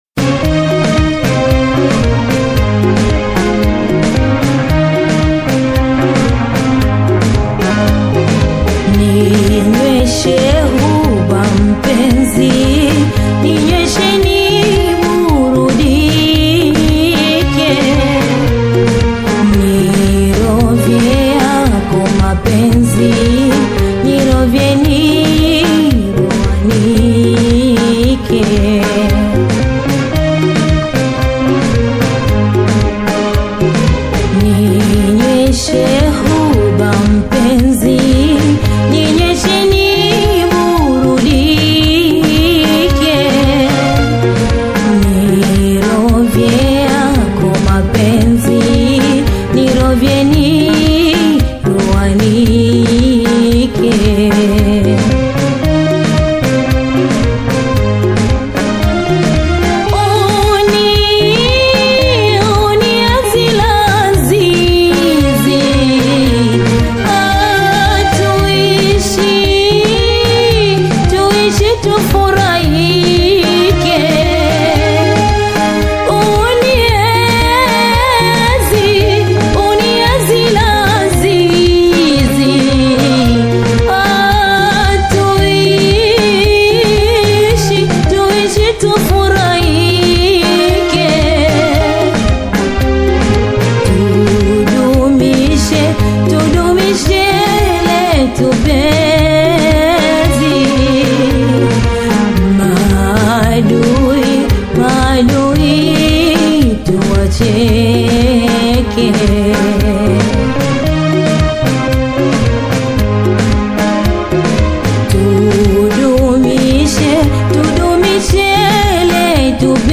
featuring the evocative vocals